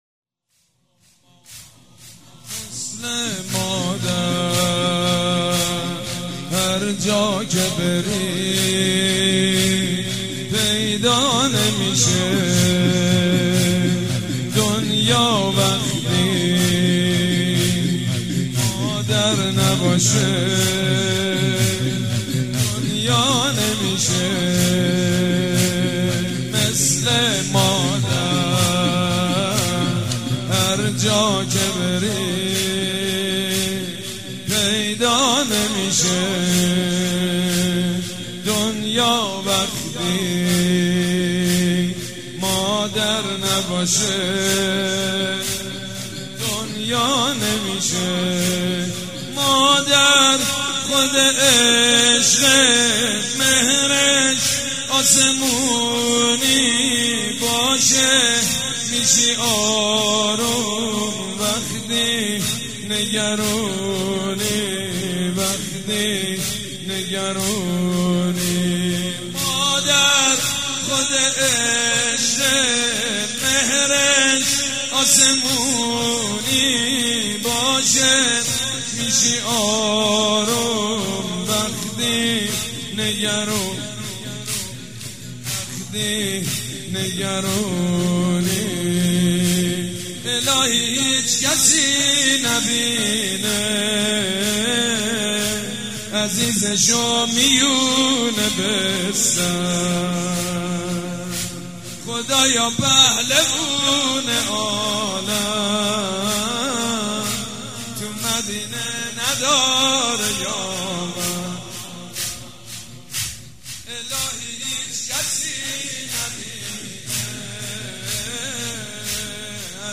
شب دوم فاطميه دوم١٣٩٤
مداح
حاج سید مجید بنی فاطمه
مراسم عزاداری شب دوم